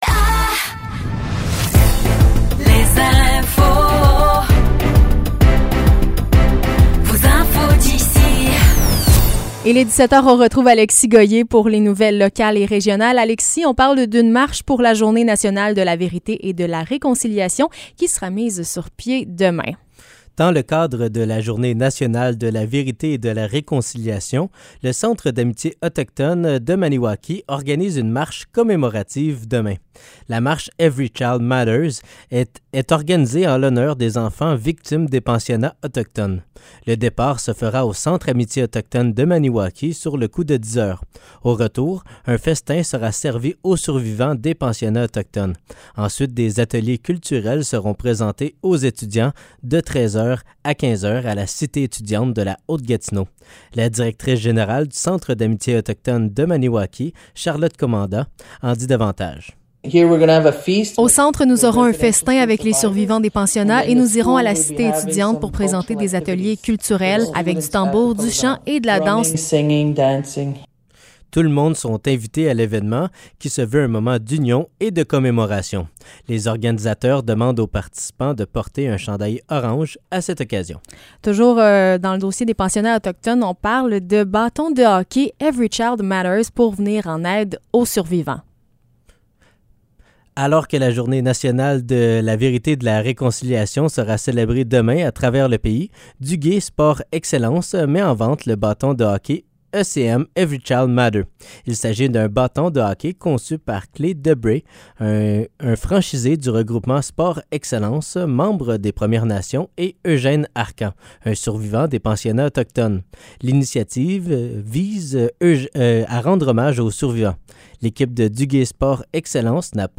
Nouvelles locales - 28 septembre 2023 - 17 h